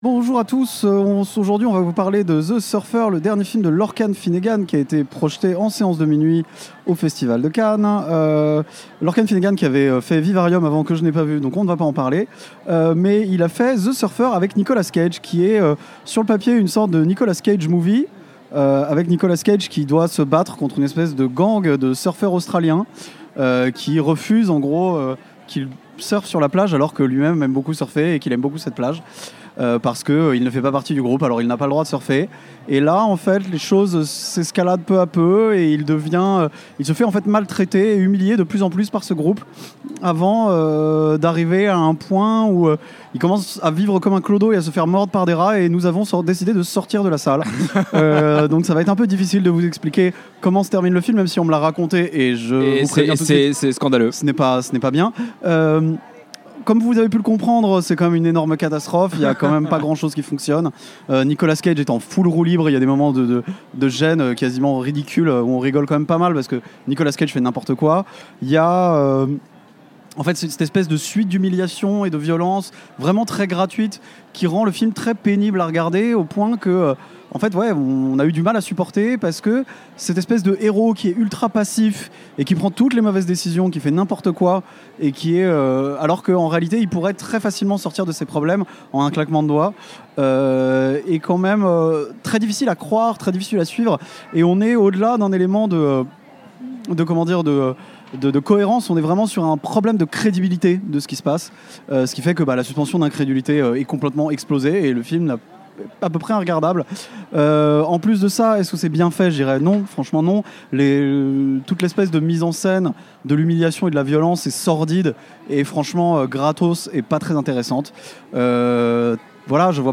Critique à chaud du film THE SURFER de Lorcan Finnegan, séance de minuit au 77ème Festival de Cannes